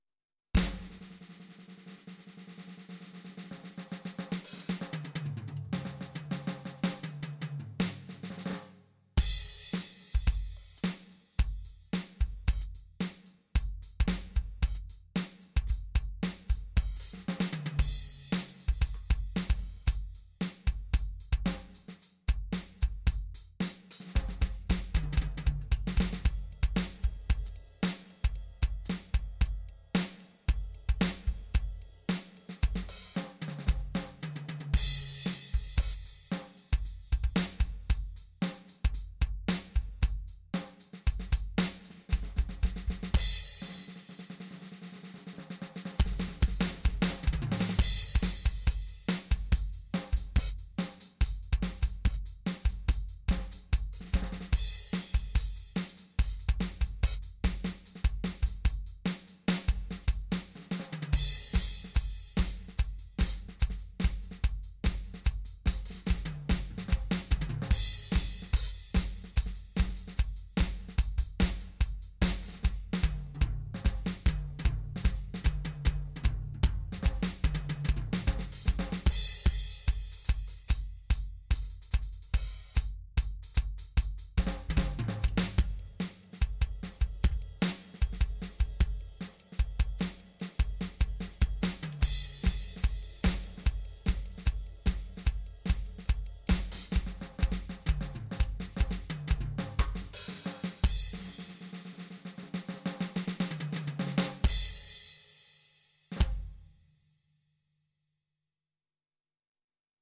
rocking out on my drums